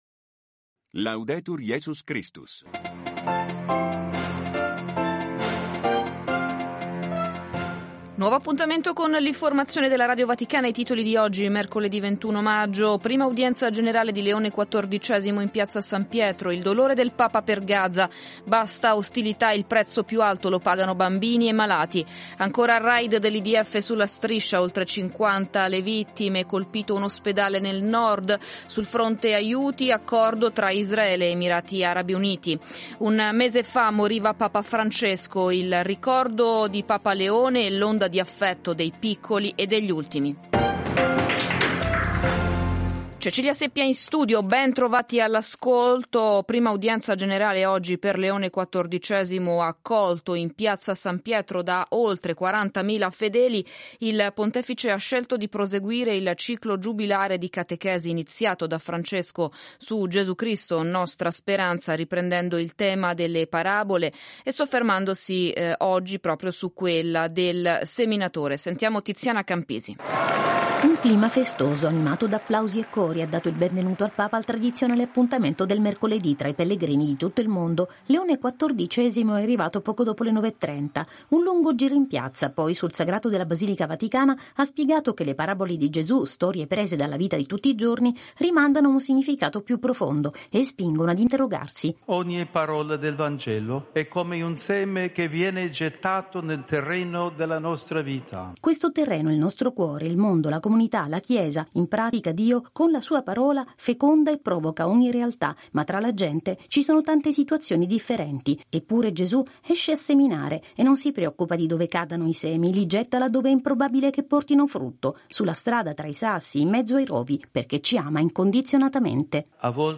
Radiogiornali di Radio Vaticana